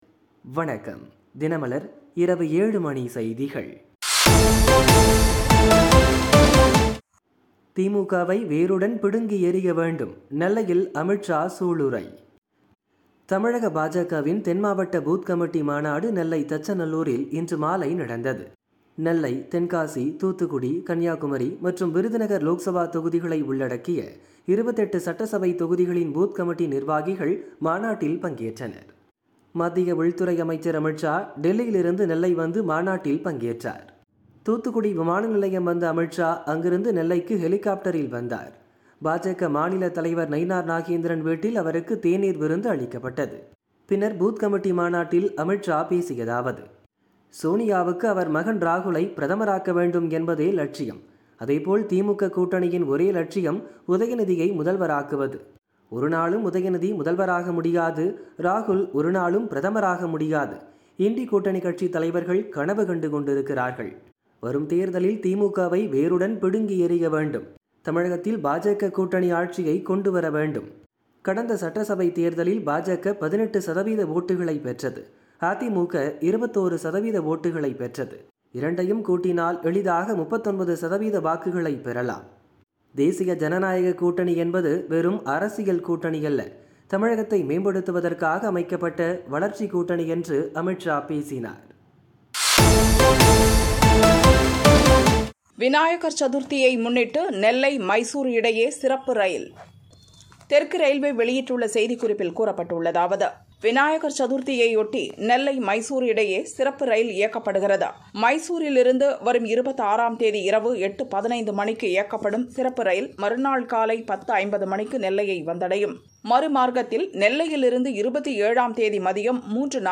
தினமலர் இரவு 7 மணி செய்திகள் - 22 August 2025